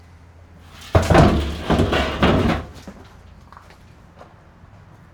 transport
Bus Metal Tank Fall Out 6